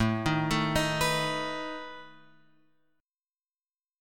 A Suspended 2nd Flat 5th